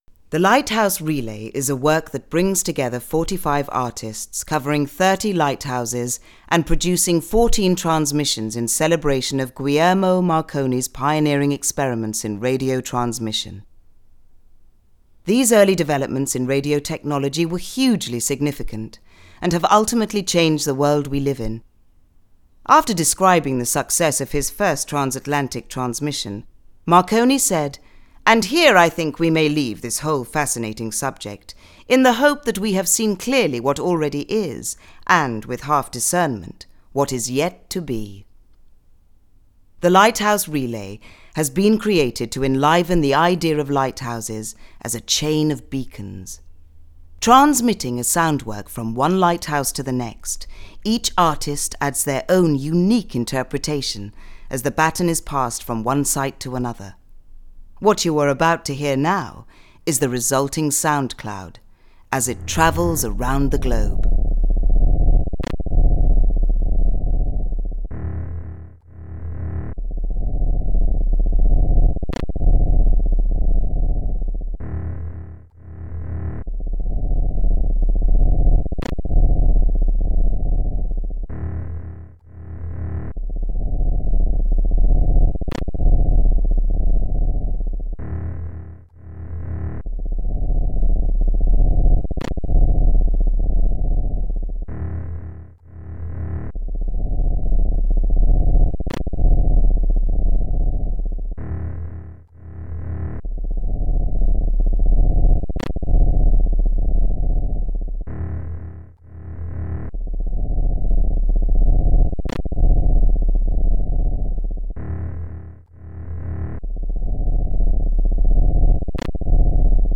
Transmission Arts & Experimental Sounds
The artists were also commissioned to collaborate on the Lighthouse Relay - the creation of a set of transitional sound pieces, via the exchange of site or concept related sound samples, that were developed as the work moved from one lighthouse site to the next.